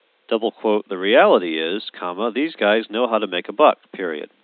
Reconstructed signal